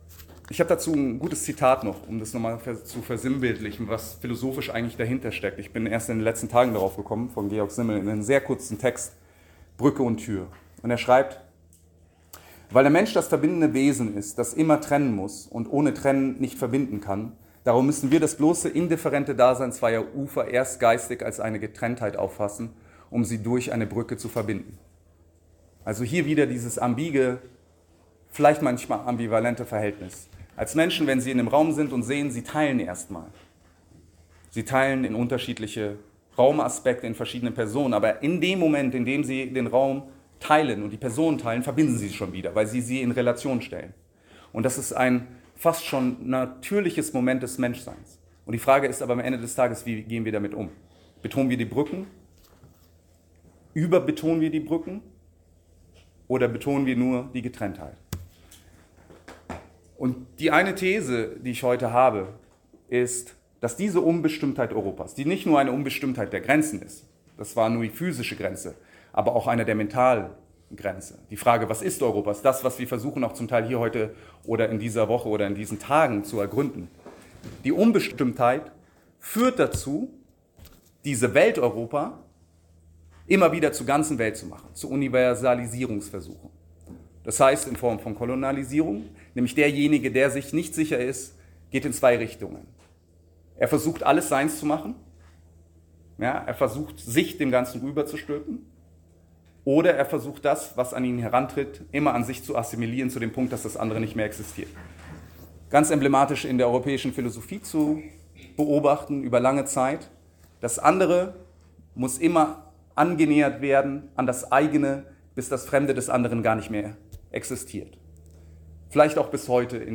Der Vortrag ist aus dem Jahr 2024, gehalten bei der SummerSchool der Akademie3.